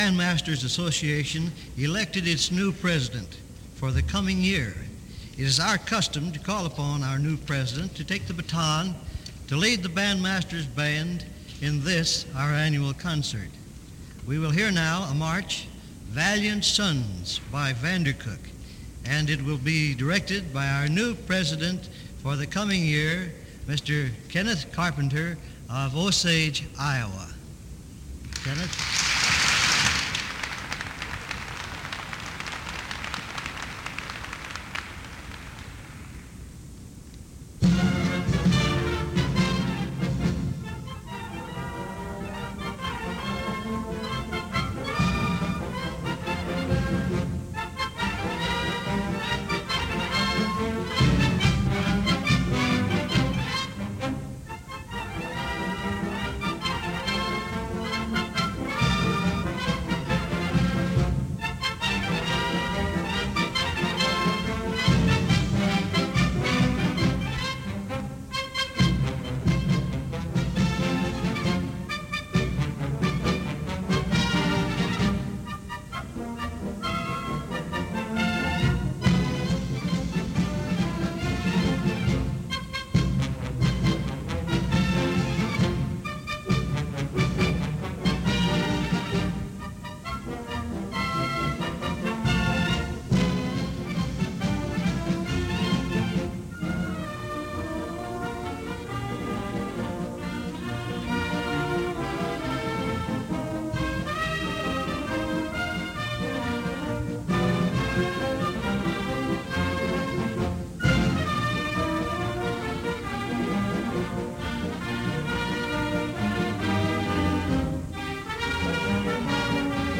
These mp3 files are from a CD that was a digital transfer from a reel-to-reel tape. It is a recording of radio WHO’s broadcast of the Iowa Bandmasters Association “Directors Band” that played at the 1951 convention in Des Moines.